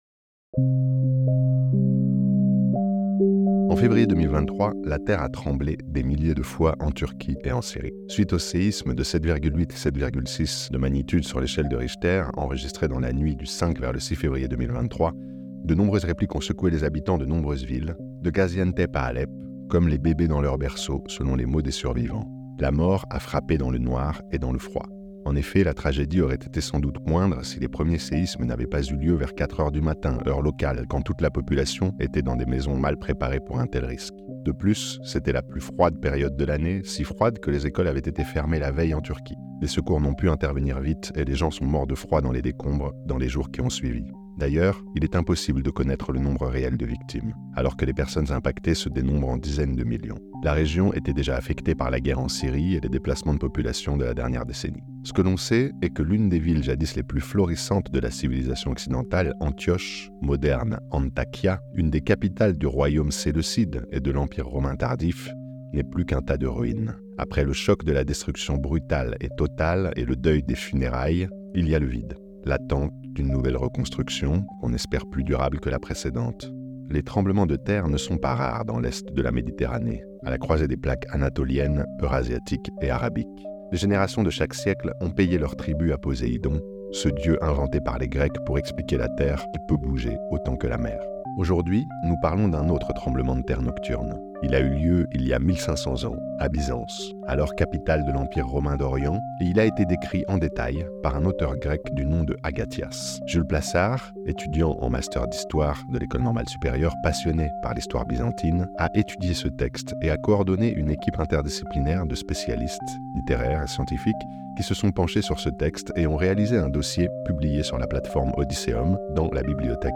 C'est ce que vous propose ce podcast avec l'intervention croisée d'historiens, d'archéologues et de sismologues et une lecture d'un extrait des Histoires d'Agathias, a